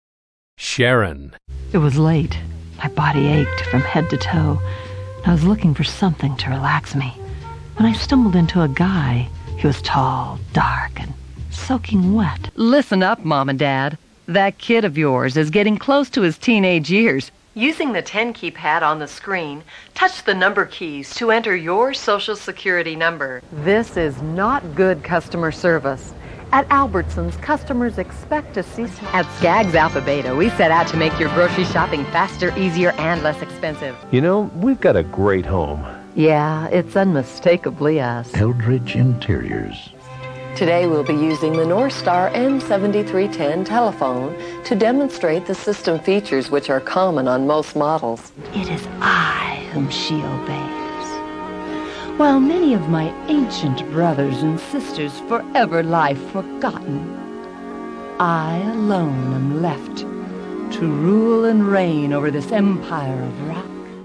Free voice over demos.